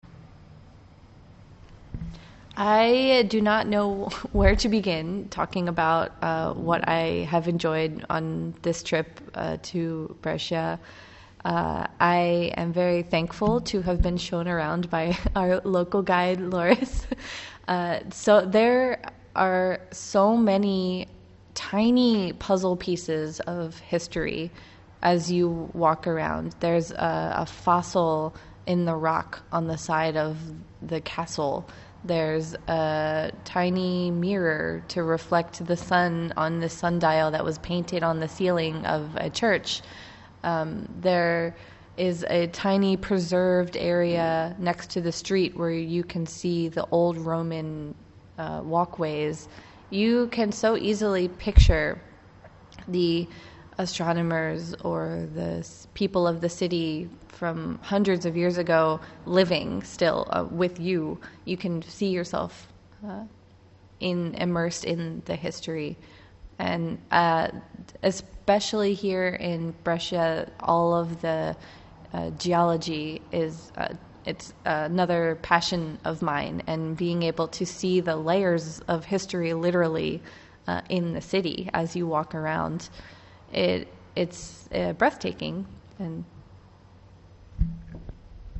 Play to listen the interview Visit to the Serafino Zani Observatory >>>Return page Memories from the “Weeks in Italy” >>>Return page Brescia recounted by…